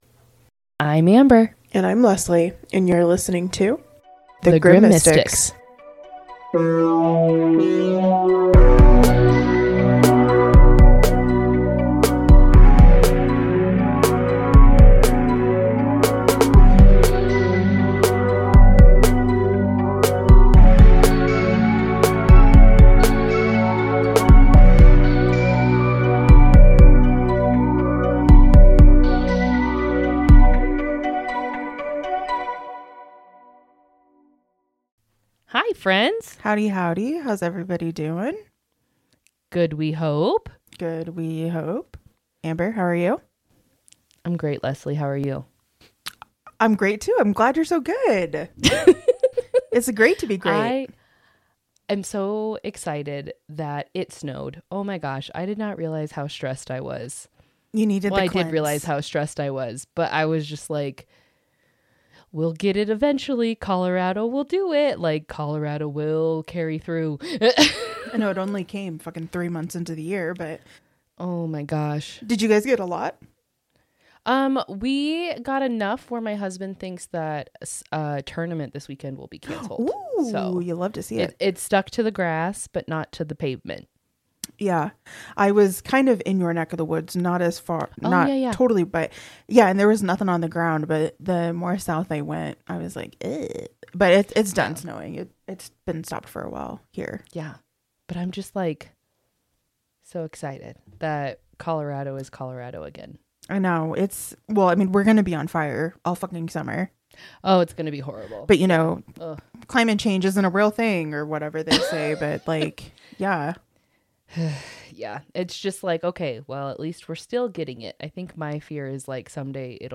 Settle in for a very chatty, very scattered, and very entertaining deep dive into the strange digital trails we leave behind.